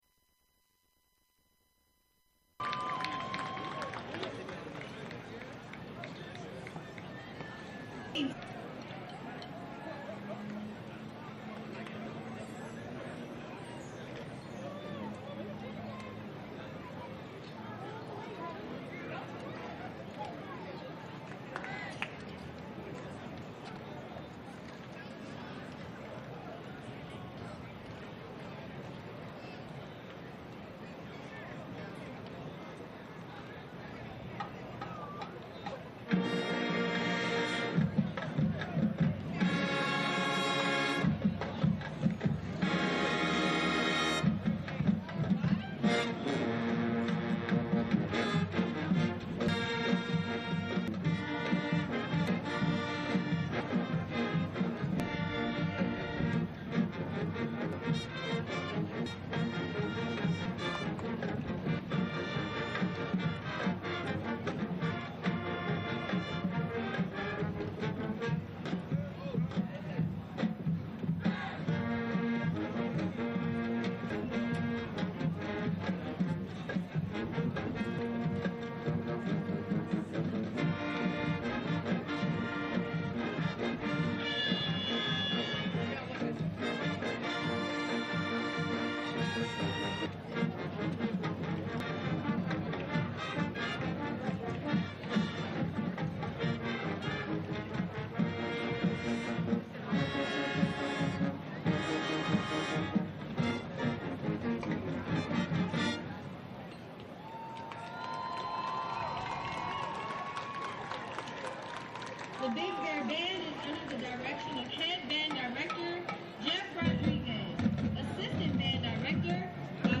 2024 from Crump Stadium